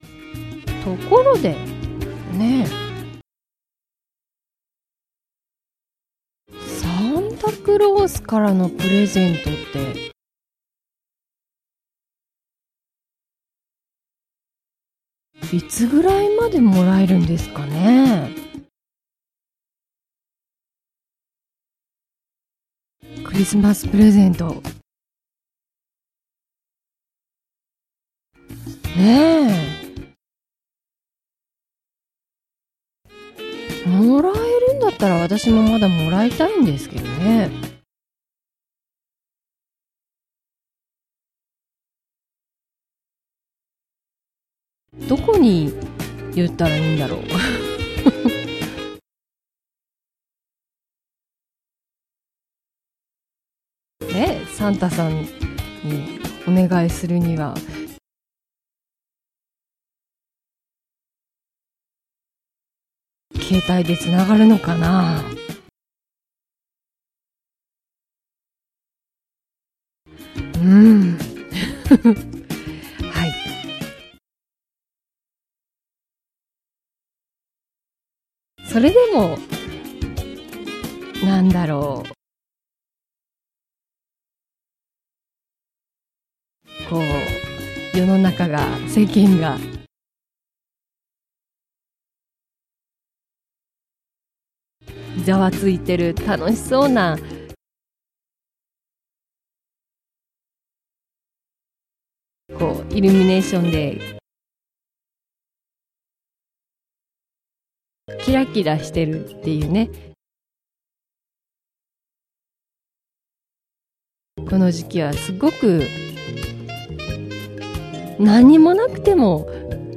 Slow Speed